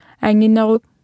Speech synthesis Martha to computer or mobile phone